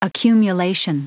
Prononciation du mot accumulation en anglais (fichier audio)
Prononciation du mot : accumulation